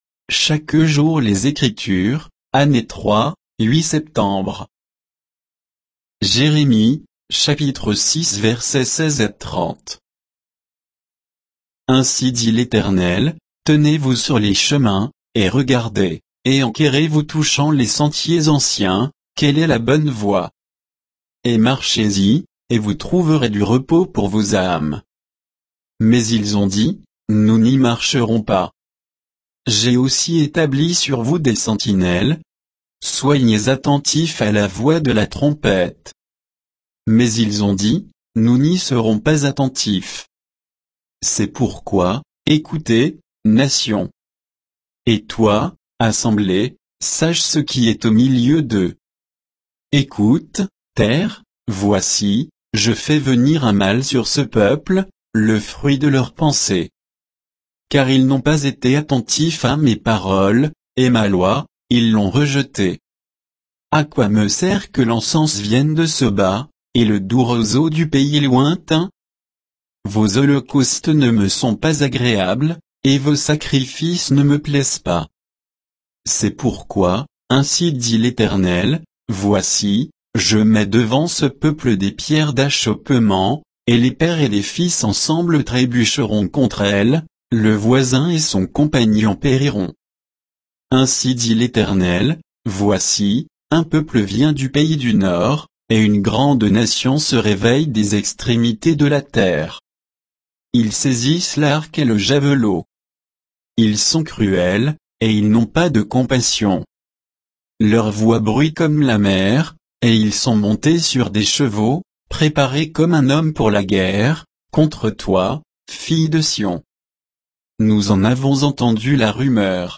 Méditation quoditienne de Chaque jour les Écritures sur Jérémie 6